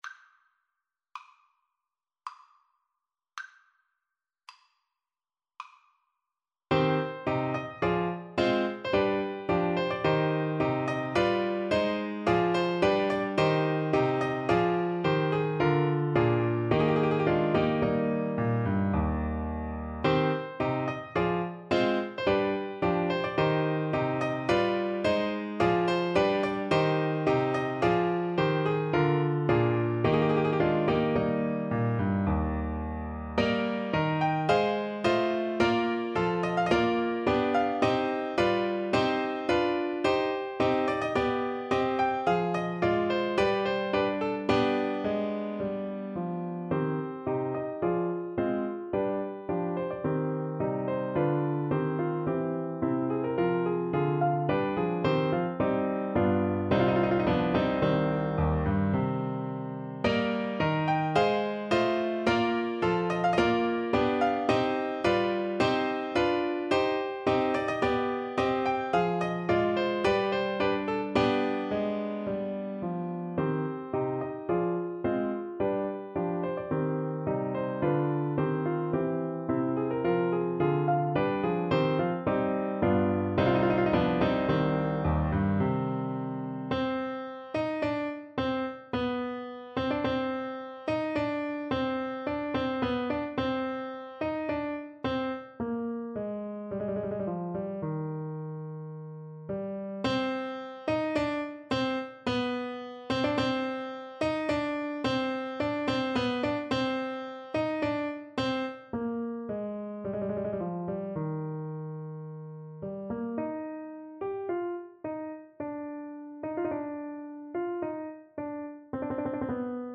~ = 54 Moderato
3/4 (View more 3/4 Music)
Classical (View more Classical Trumpet Music)